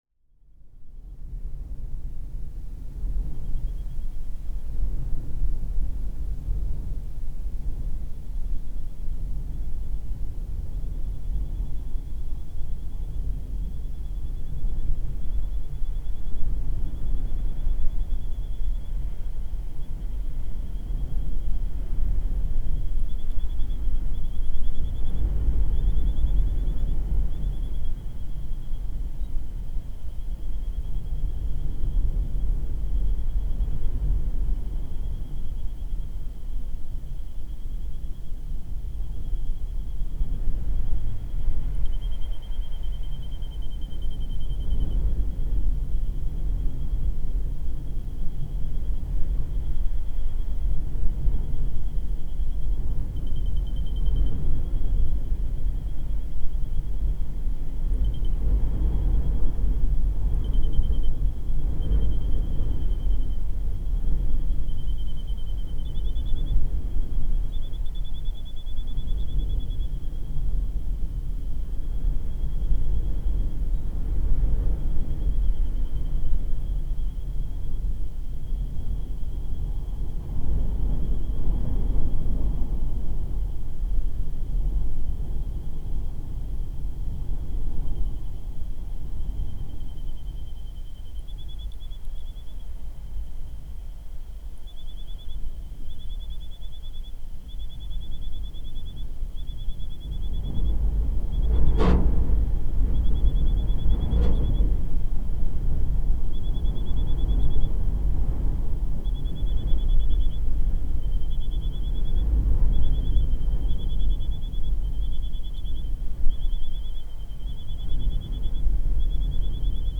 PFR05572, desert storm
Betpak Dala, Kazakhstan